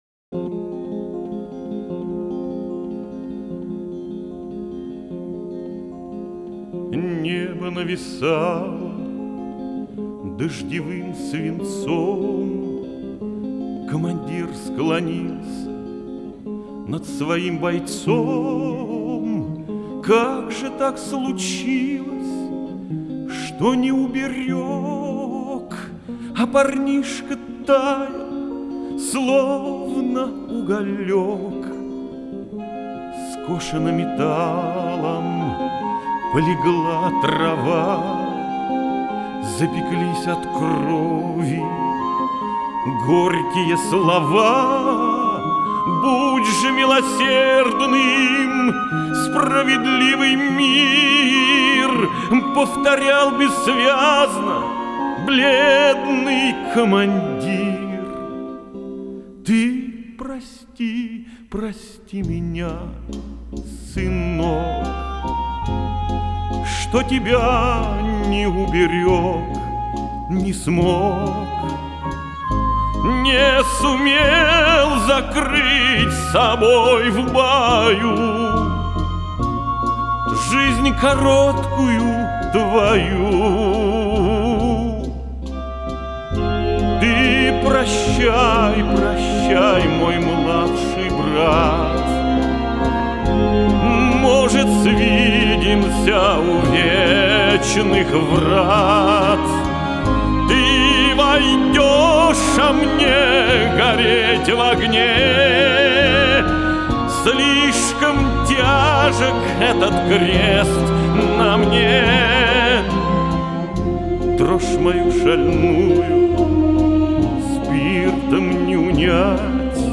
Описание: Советская песня или новый состав ?